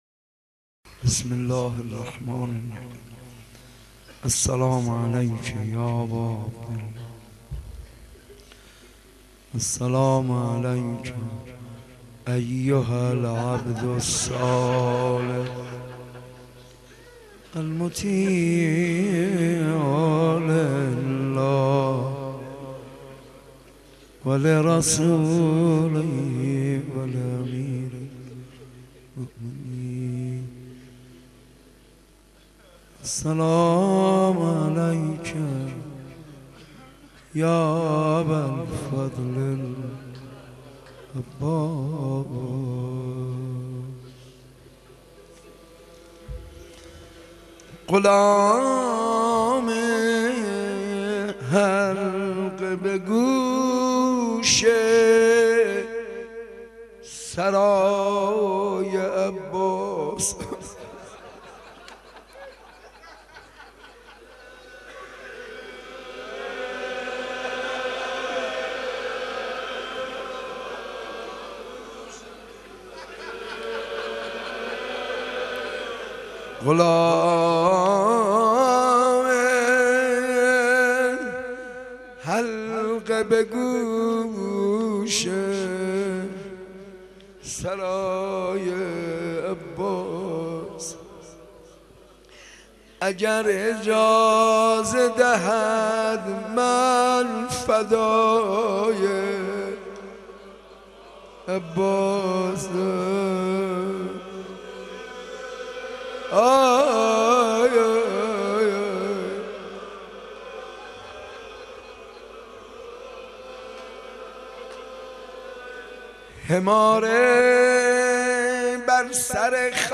حاج منصور ارضی مداح
مناسبت : تاسوعای حسینی
قالب : مجلس کامل
roze 09 moharram 83 ark.mp3